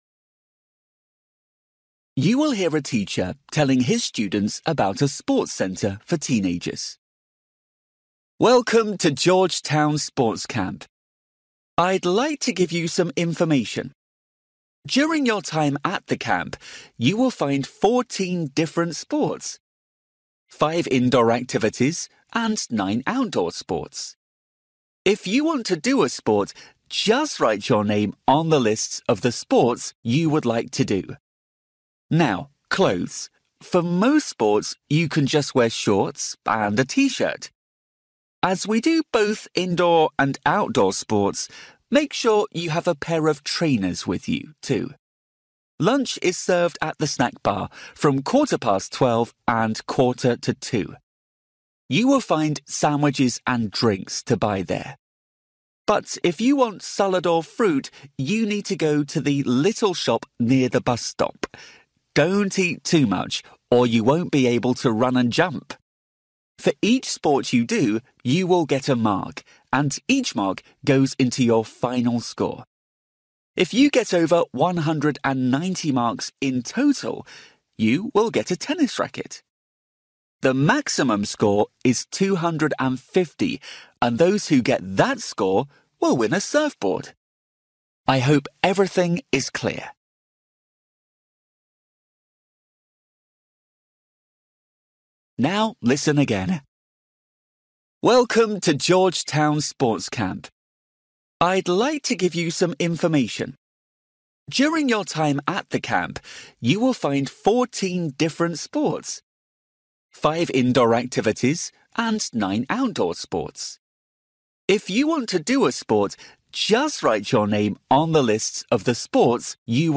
You will hear a teacher telling his students about a sports centre for teenagers.